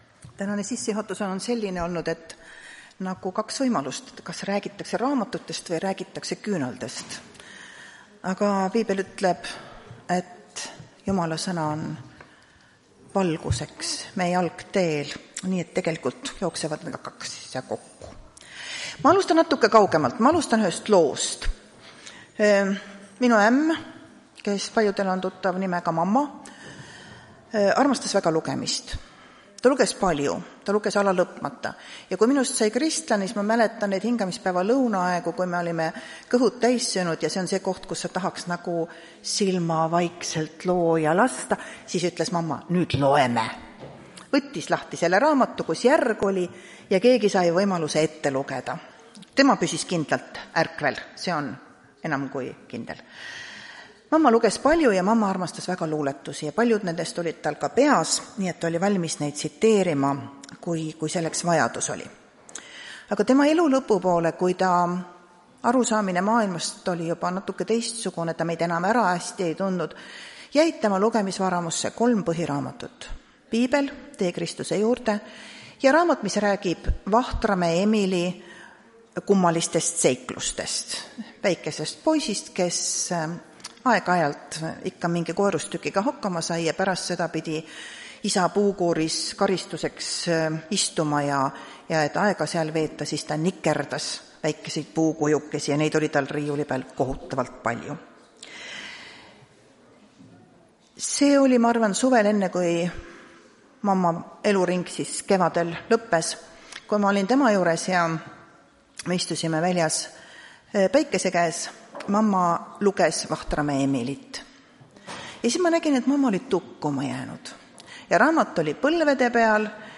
Tartu adventkoguduse 02.11.2024 hommikuse teenistuse jutluse helisalvestis.